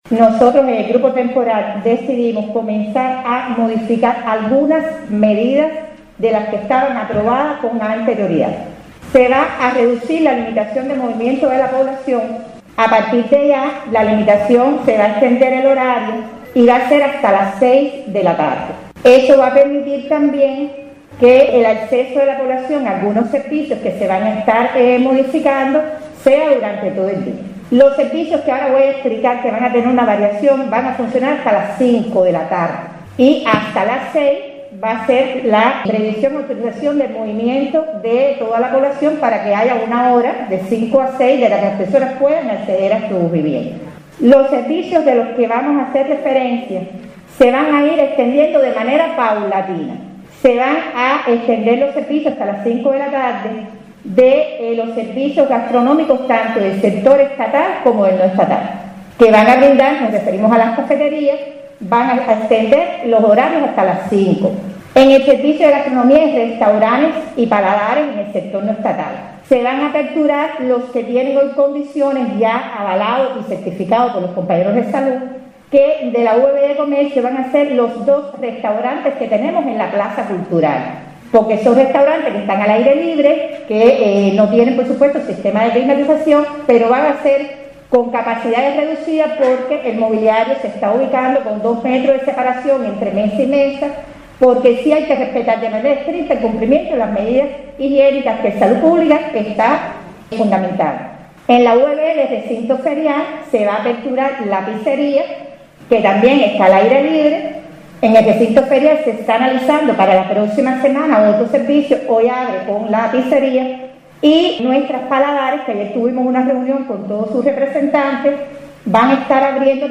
Sobre las disposiciones a cumplir en la presente etapa conocimos a través de la intendente del municipio, María Elena Rodríguez Pérez.